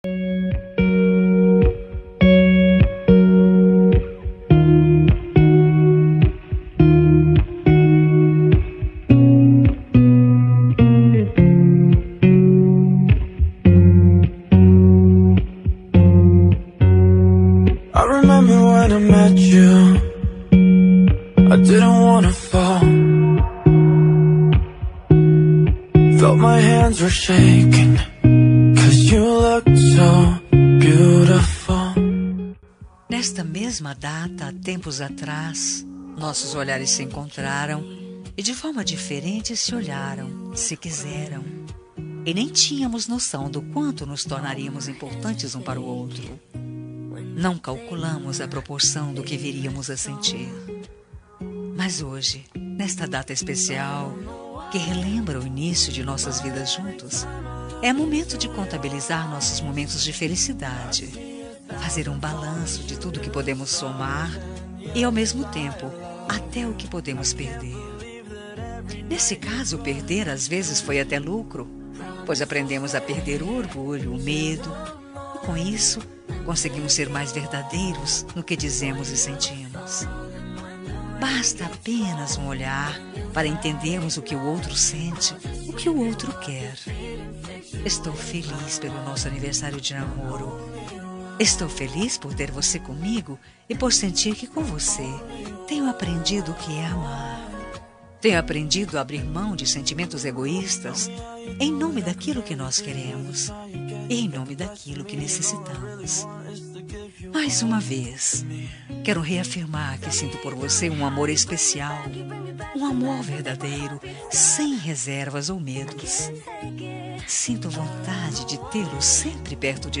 Telemensagem Aniversário de Namoro – Voz Feminina – Cód: 8094 – Linda.